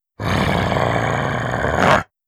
Monster Roars
19. Intimidation Growl.wav